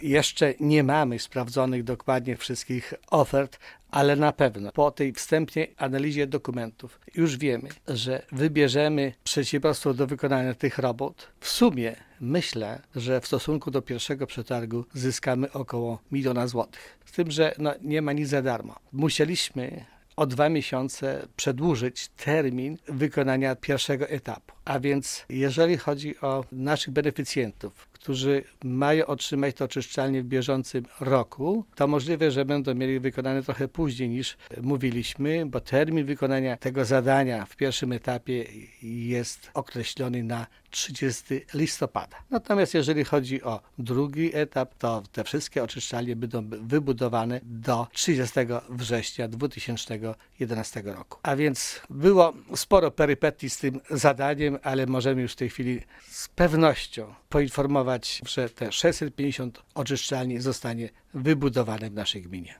„Jednak w związku z wydłużeniem procedury przetargowej I etap realizacji tej inwestycji będzie przesunięty o kilka tygodni” – informuje zastępca wójta Wiktor Osik: